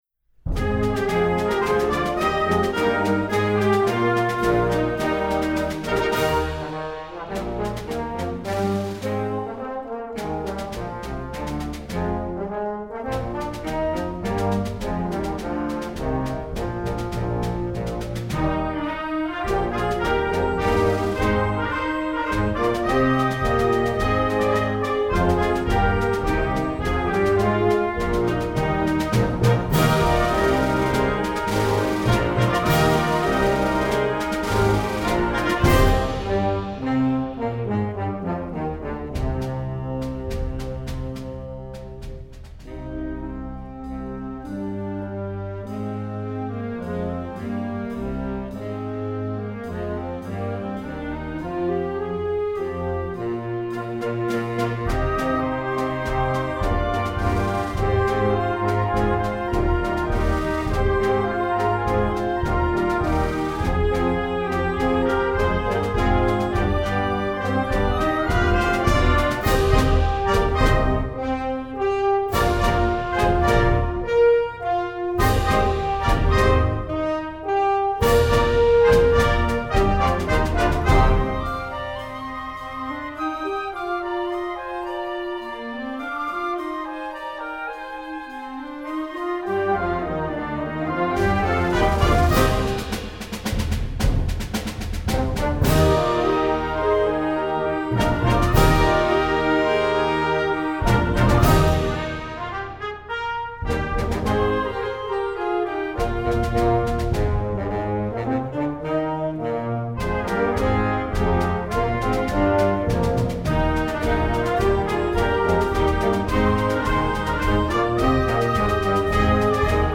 Instrumentation: concert band
instructional